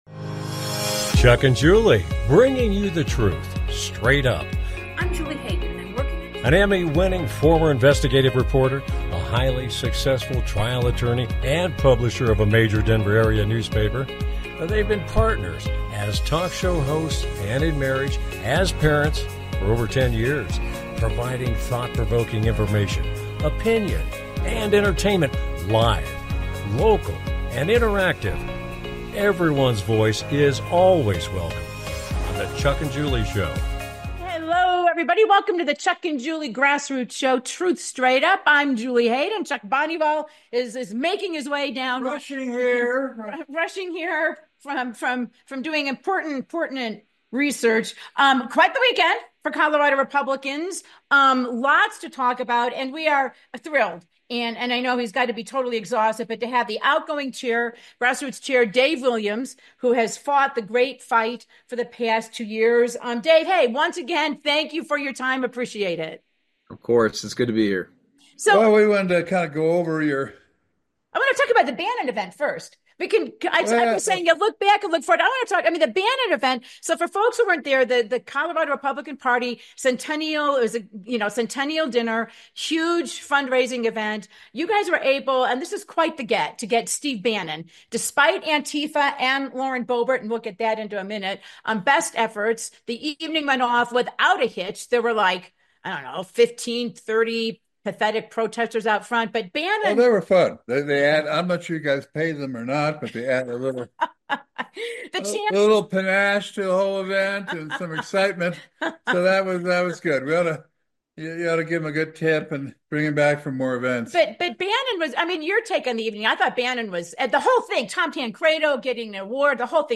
The UniParty/Establshment narrowly beat the grassoots to take back control of the Colorado Republican Party. Outgoing grassroots Chair Dave Williams joins the show with a look back and a look forward.